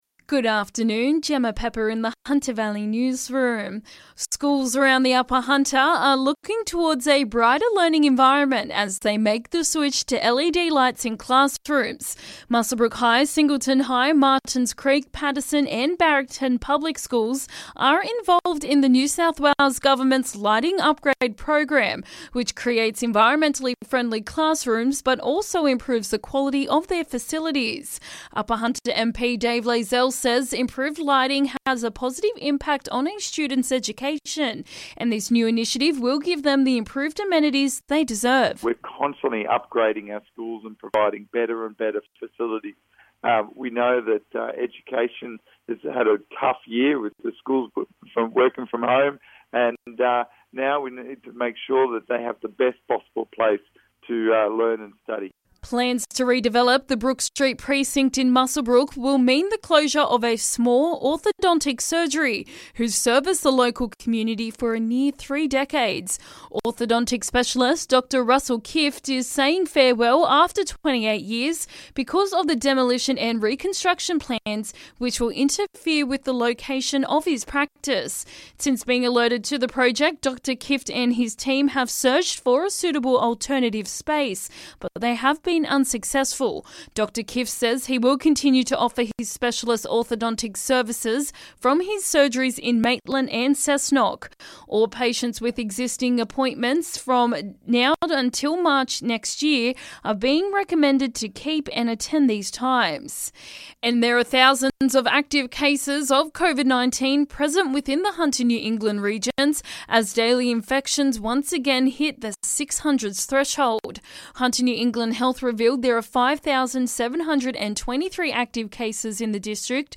LISTEN: Hunter Valley Local News Headlines 22/12/2021